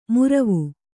♪ muravu